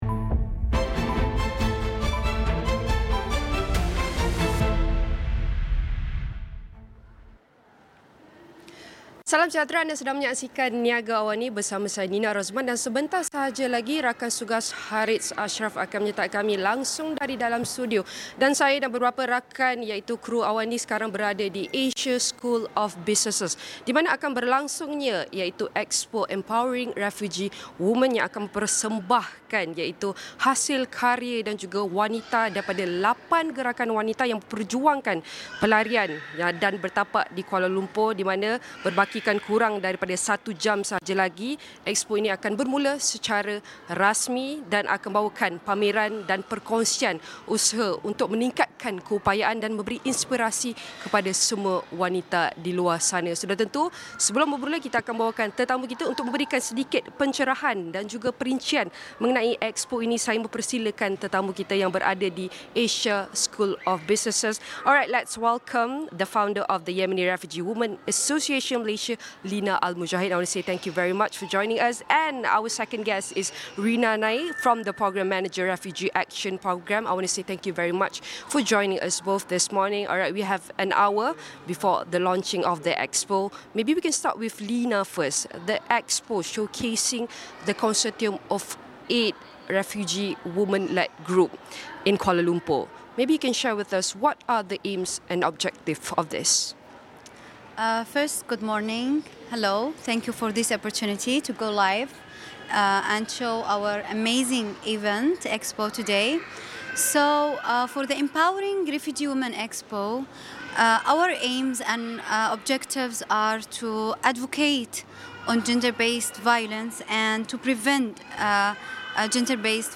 Niaga AWANI bersiaran langsung di Asia School of Business bermula jam 9.00 pagi.